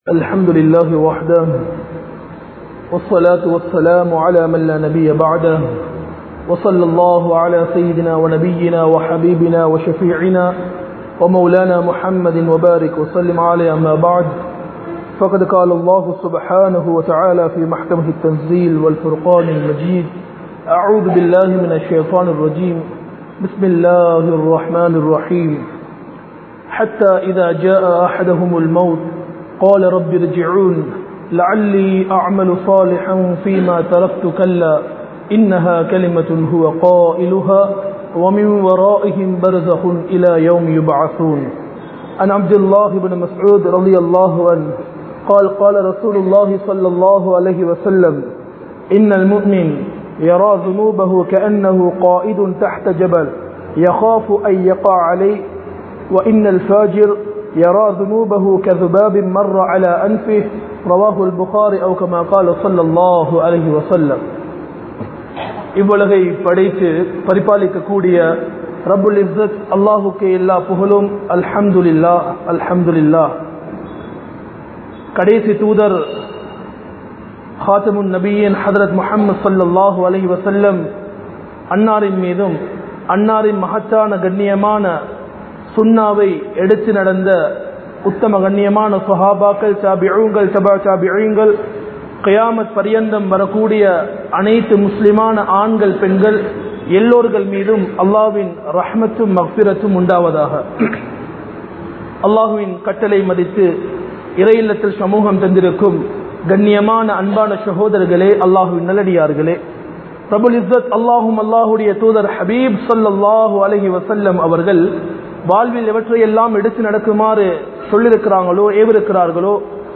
Amalahalil Munthikollungal (அமல்களில் முந்திக் கொள்ளுங்கள்) | Audio Bayans | All Ceylon Muslim Youth Community | Addalaichenai
Mallawapitiya Jumua Masjidh